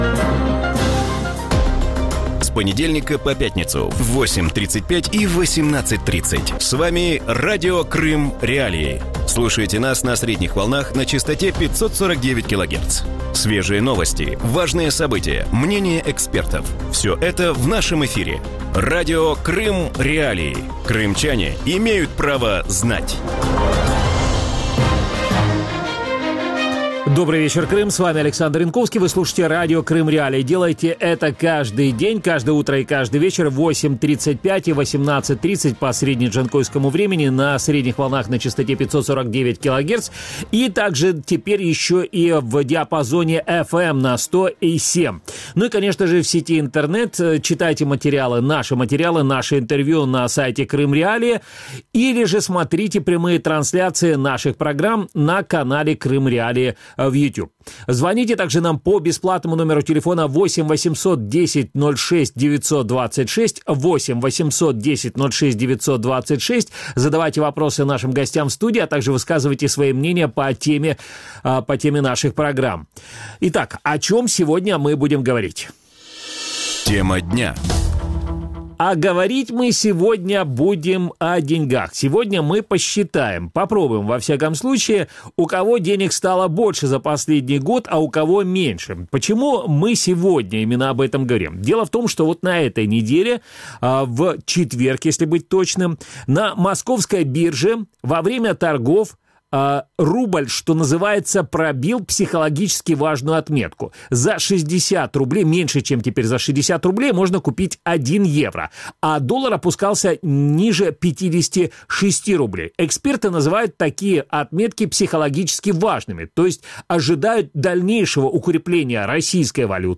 В вечернем эфире Радио Крым.Реалии обсуждают курс российской валюты и его влияние на политическую обстановку в стране. Как долго будет продолжаться укрепление российского рубля? Какие факторы способствуют росту курса российской валюты?